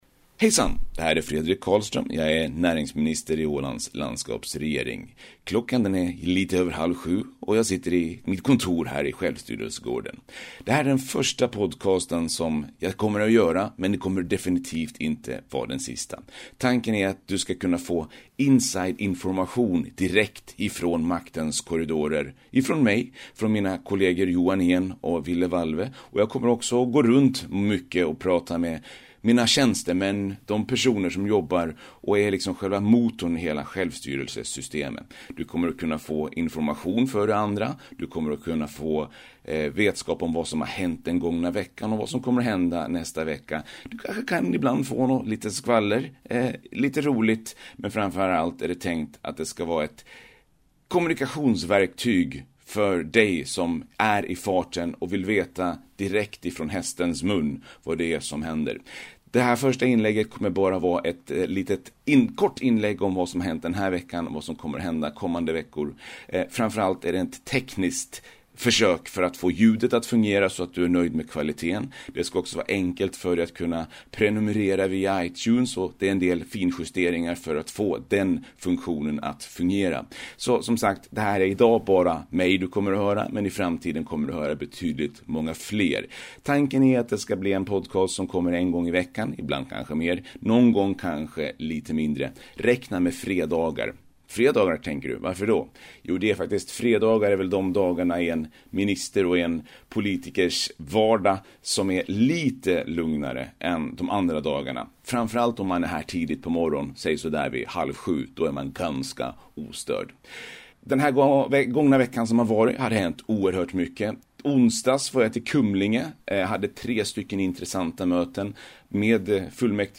Det här är den första ”test” podcasten på den här bloggen, den är idag inte så innehållsrik utan i första hand ett försök för att få alla inställningar rätt, ljudkvaliteten bra, synkroniseringen till iTunes att fungera så att det skall vara lätt för dig att kunna följa/abonnera på den om du väljer att göra så.